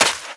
High Quality Footsteps
STEPS Sand, Run 04.wav